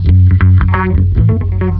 5 Harsh Realm Bass Riff 1.wav